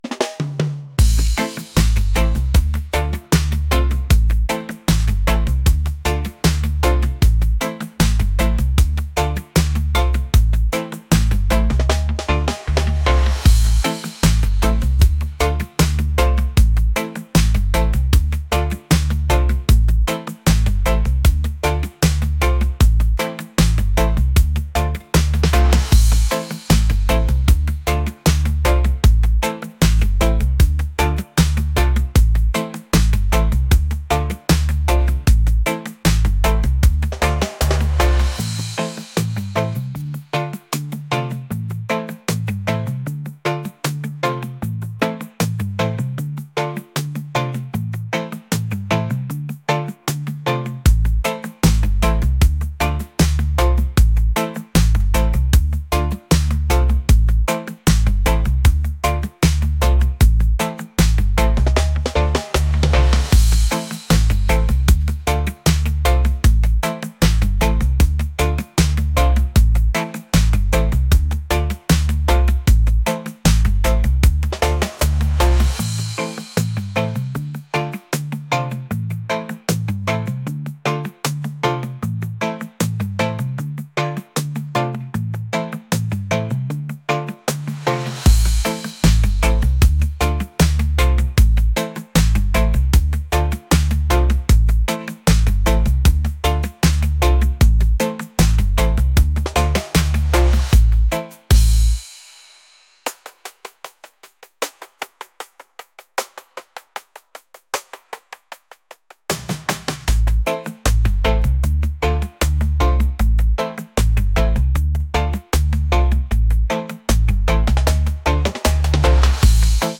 reggae | lounge